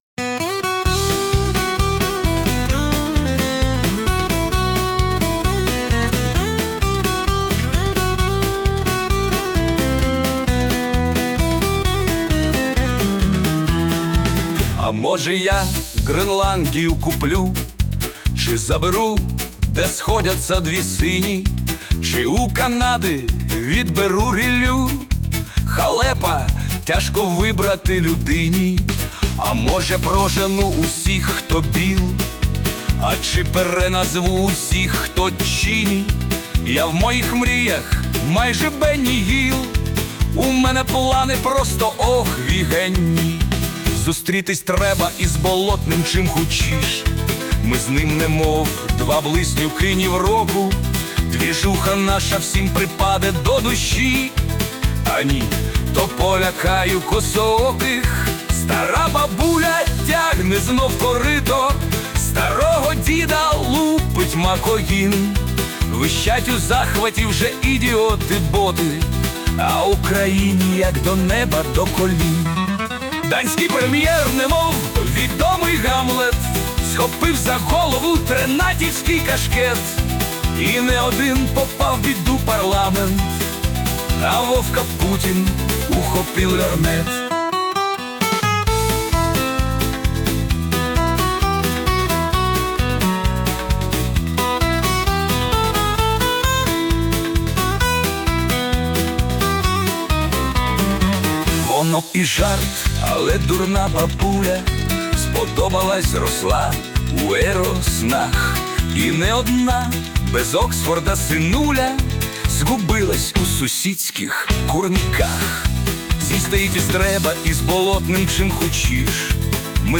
Музичне прочитання з допомогою ШІ